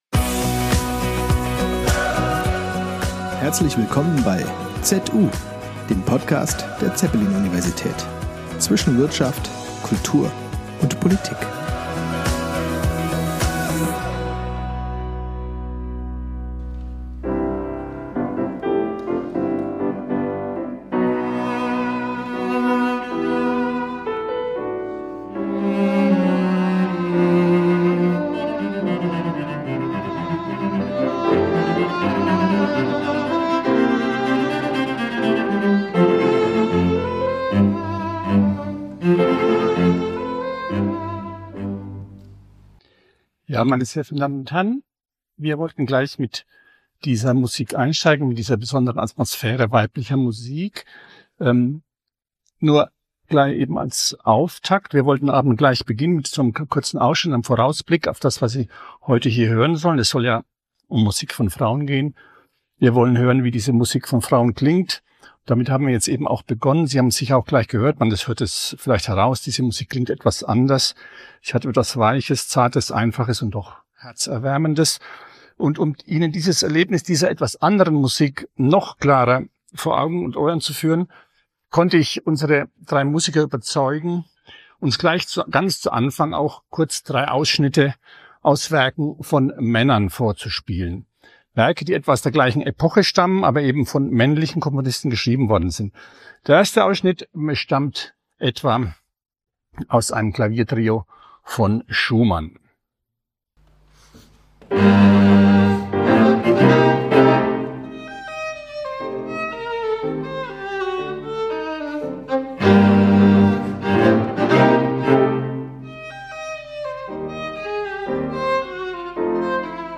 Kammermusik von KomponistINNEN | Gesprächskonzert ~ ZU
Im Gesprächskonzert des artsprogram werden hingegen selten gespielte Klaviertrios der Komponistinnen Fanny Hensel, Lili Boulanger und Mel Bonis zu hören sein. Die Moderation lädt ein zum genauen Hinhören und stellt die Frage nach der „Weiblichkeit“ dieser Musik.